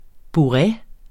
bourrée substantiv, fælleskøn Bøjning -n, -r, -rne Udtale [ buˈʁε ] Oprindelse fra fransk bourrée egentlig perfektum participium femininum af bourrer 'proppe (fuld)' Betydninger 1.